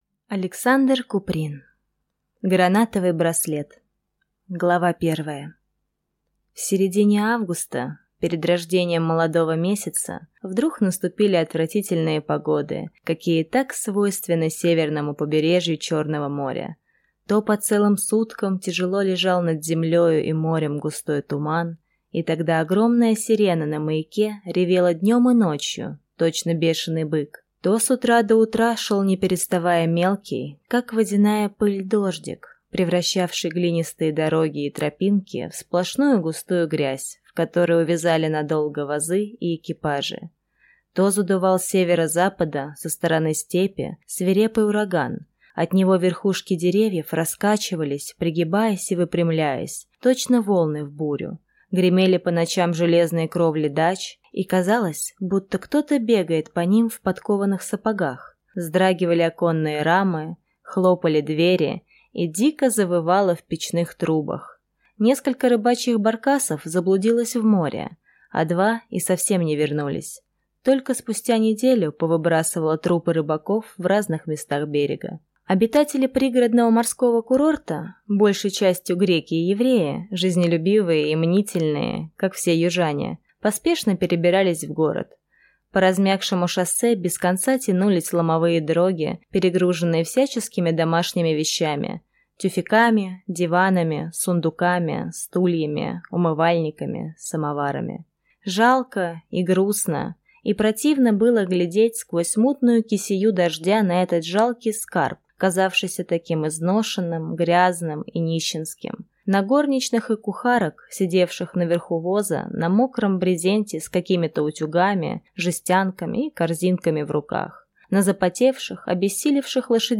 Аудиокнига Гранатовый браслет | Библиотека аудиокниг